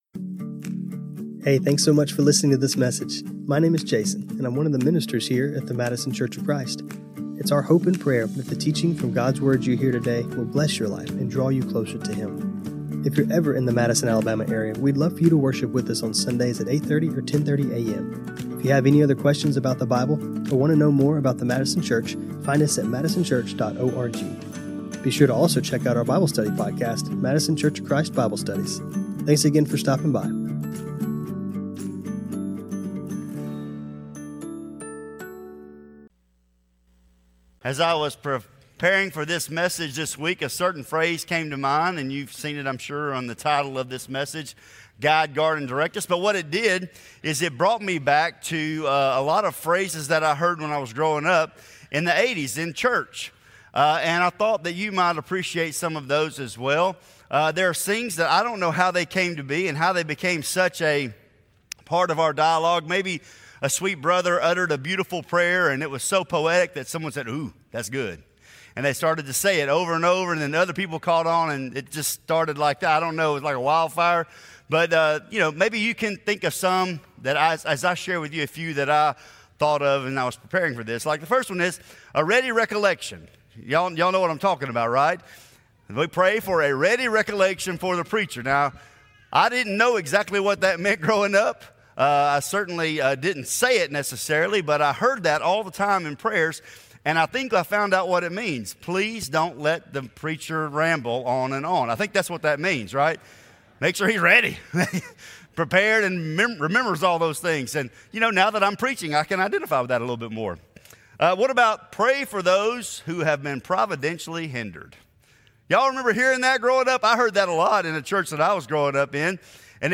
This sermon was recorded on Apr 12, 2026.